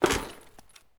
0335ec69c6 Divergent / mods / Soundscape Overhaul / gamedata / sounds / material / actor / step / tin1.ogg 35 KiB (Stored with Git LFS) Raw History
tin1.ogg